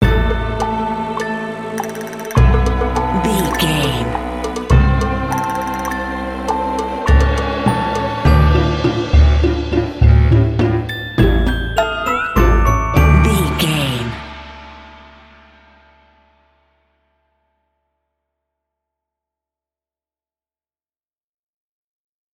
Uplifting
Dorian
percussion
flute
piano
orchestra
double bass
silly
goofy
comical
cheerful
perky
Light hearted
quirky